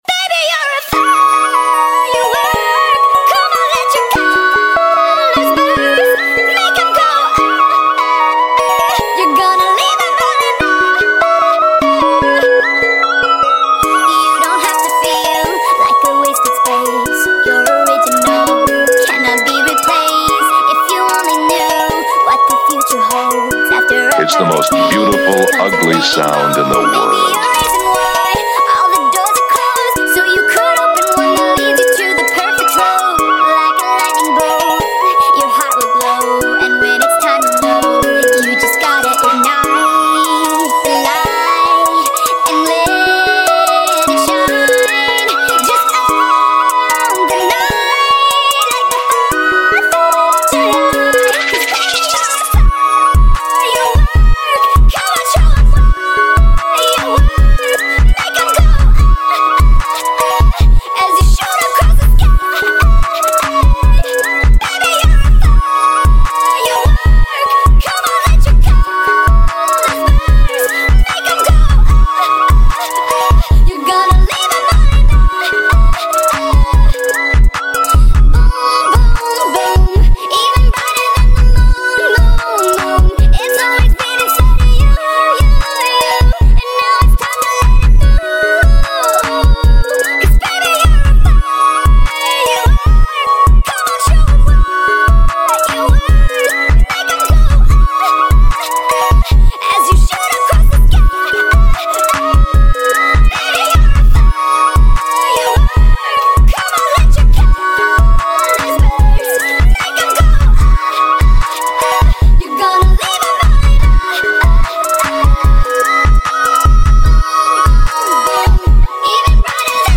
pluggnb remix